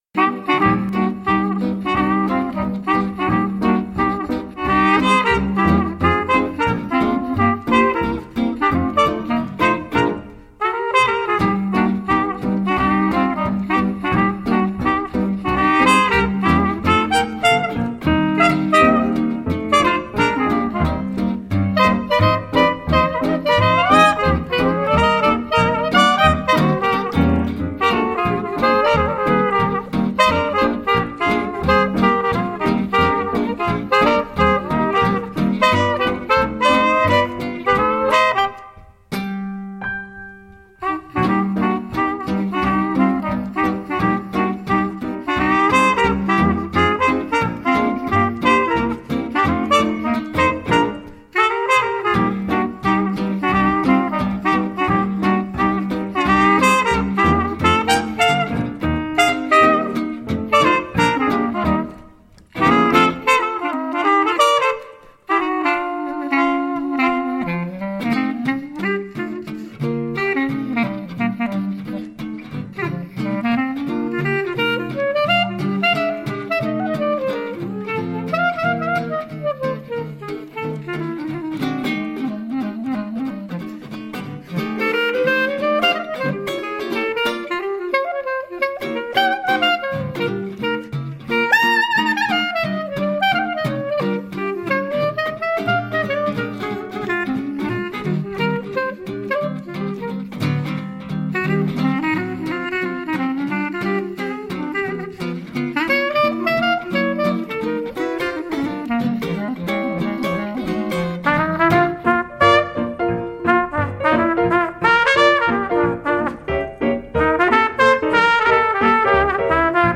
clarinette
trompette, chant
piano
guitare